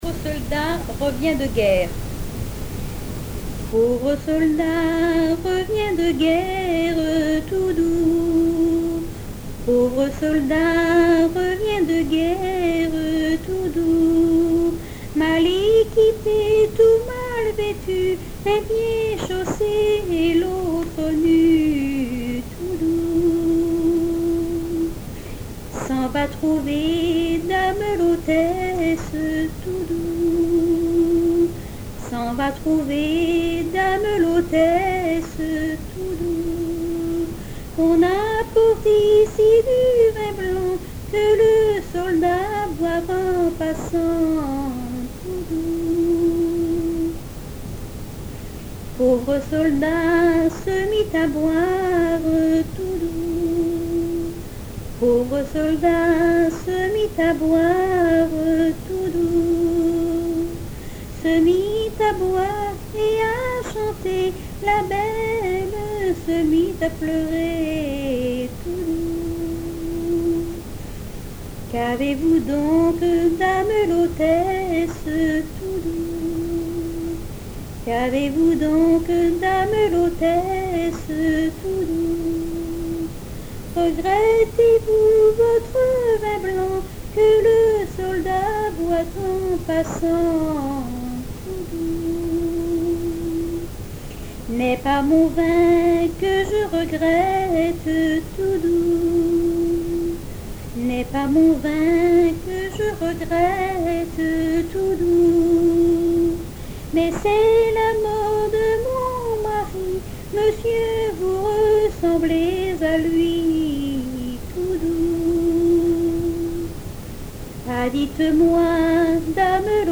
Genre strophique
chansons de variété et traditionnelles